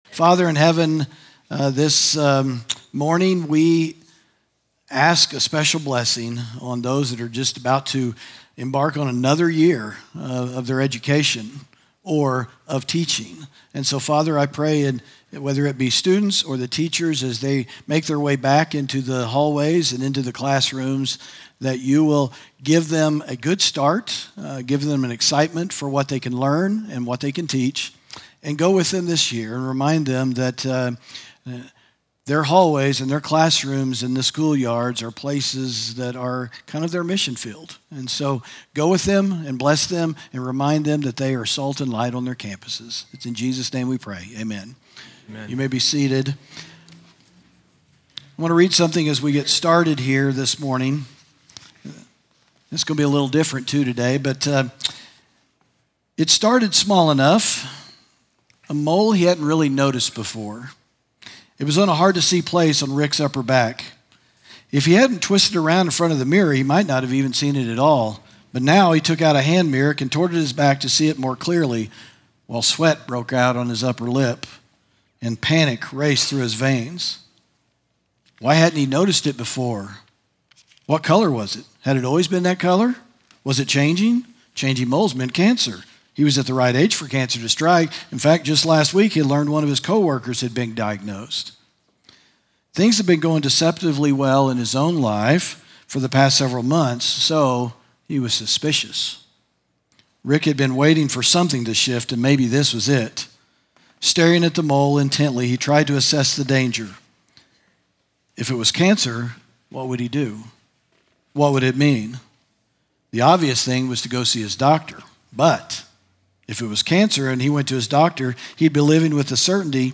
sermon audio 0810.mp3